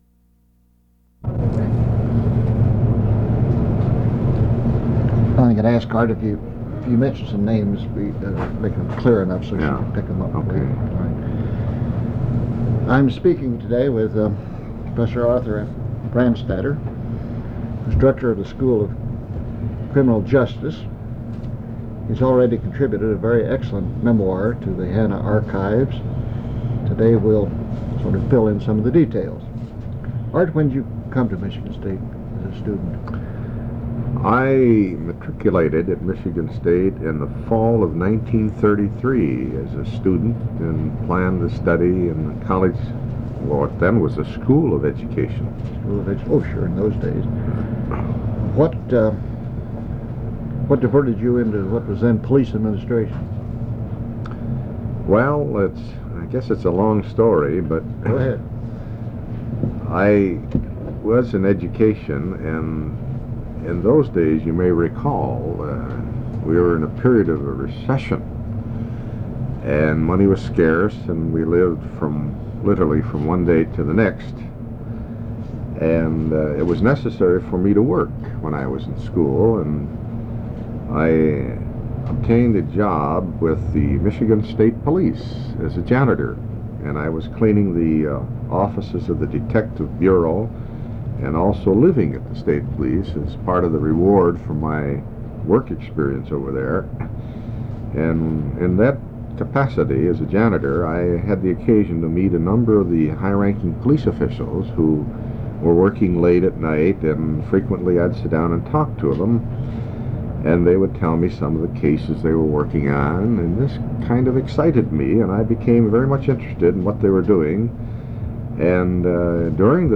Interview
Original Format: Audio cassette tape